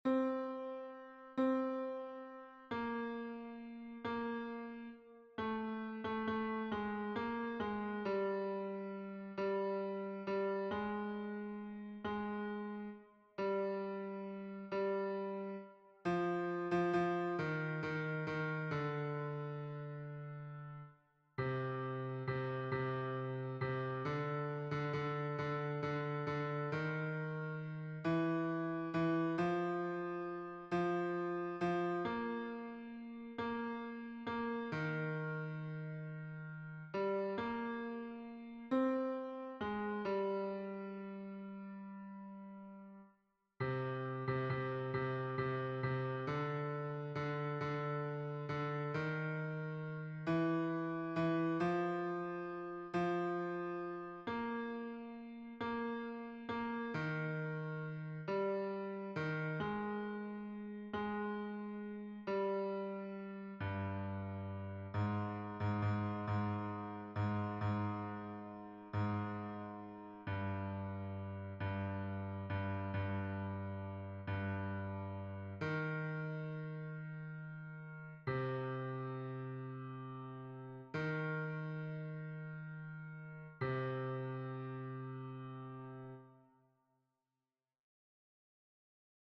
Version piano
Basse